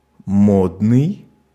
Ääntäminen
Ääntäminen France: IPA: [e.le.gɑ̃]